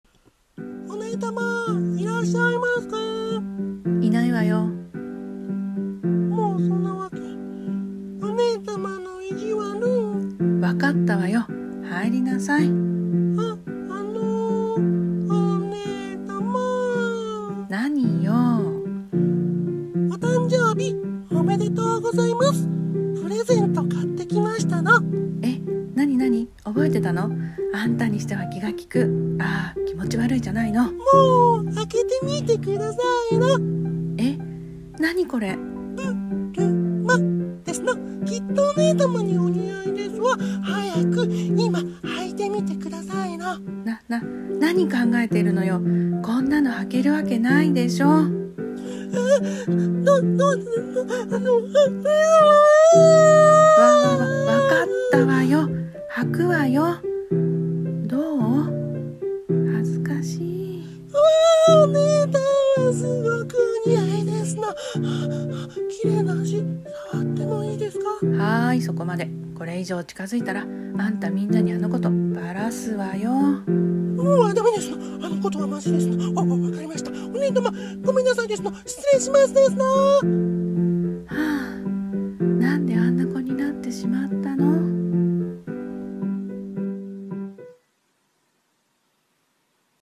【声劇】変態妹とツンデレお姉様の日常